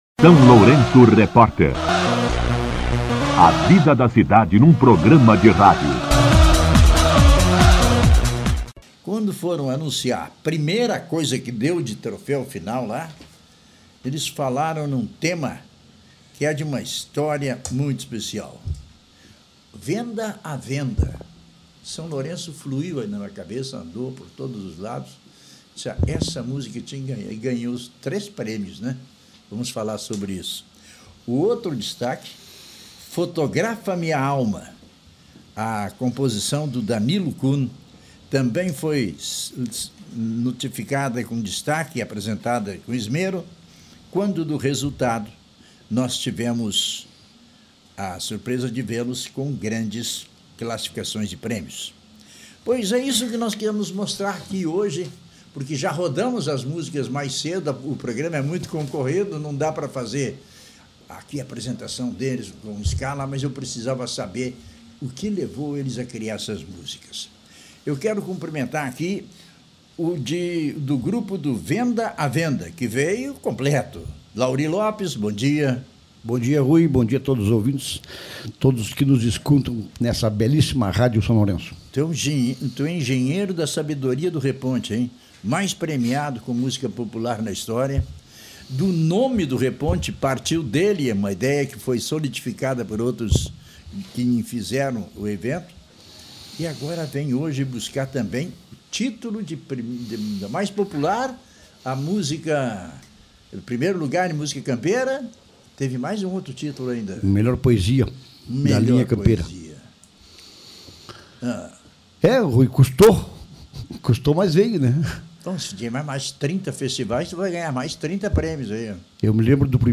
Os artistas lourencianos participaram do SLR RÁDIO na manhã desta segunda-feira (24), comentando a conquista e a representatividade do município no festival.
entrevista-reponte.mp3